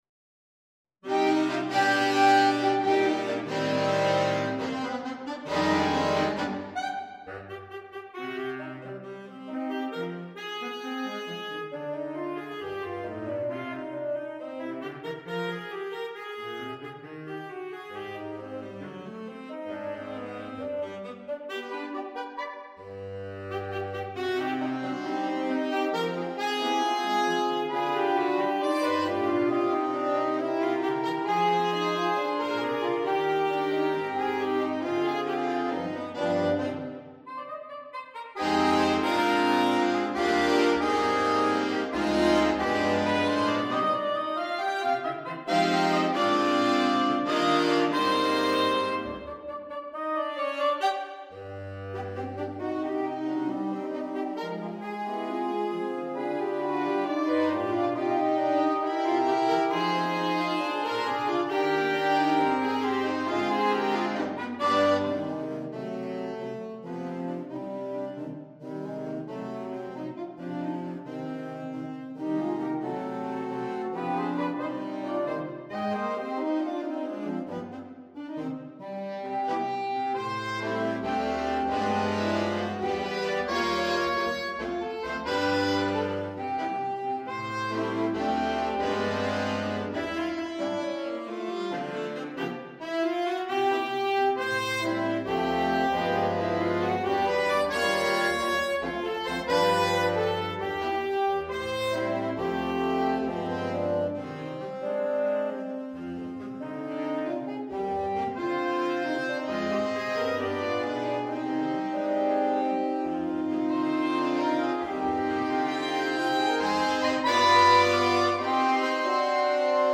S . AA . A/T . T . B
is a tango feature for tenors!
Soprano Saxophone
Alto Saxophone 1
Tenor Saxophone 1
Baritone Saxophone
produced using NotePerformer through Sibelius software.